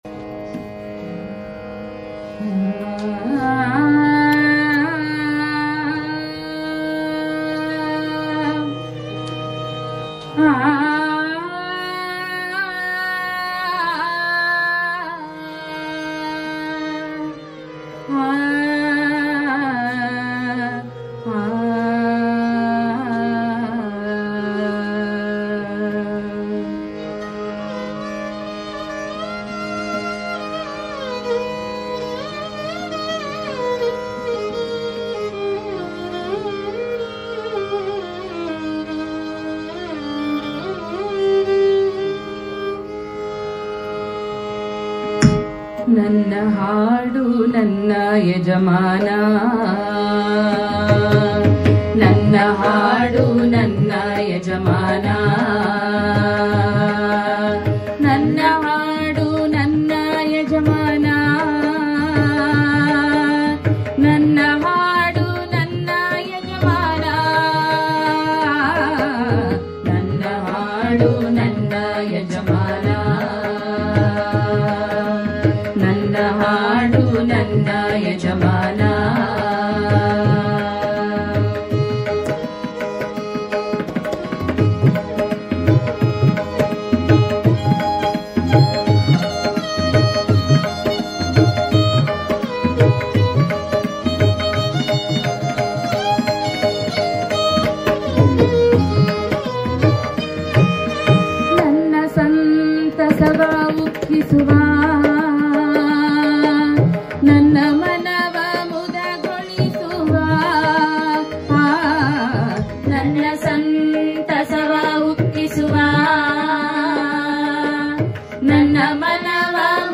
ವಿಶೇಷವೆಂದರೆ ಈ ಕವಿತೆ ರಾಗ ಸಂಯೋಜನೆಗೊಂಡು ಸಾಹಿತ್ಯ-ಸಂಗೀತ ಪ್ರಿಯರ ಮನಸೂರೆಗೊಂಡಿದೆ.
ವಯೋಲಿನ್
ತಬಲ